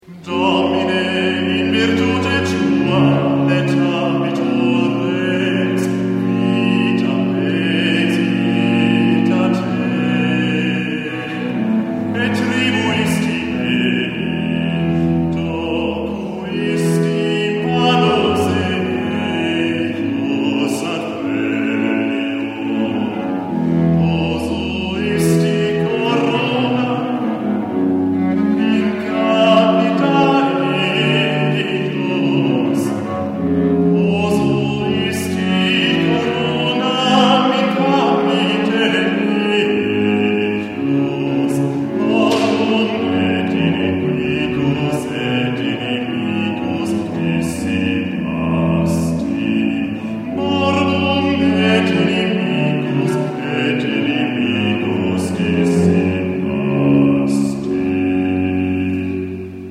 Bass und Bassbariton für "Alte Musik"